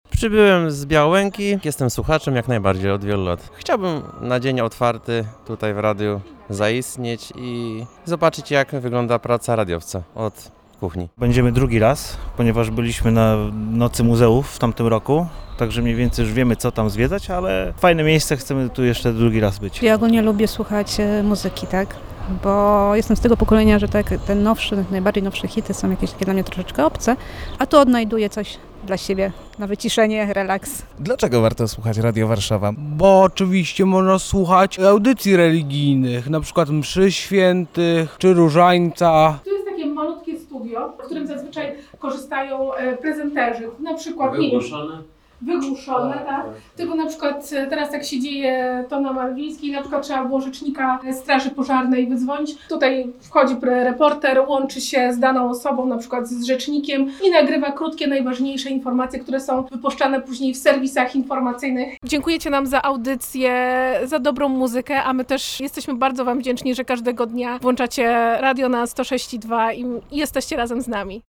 spec-sluchacze.mp3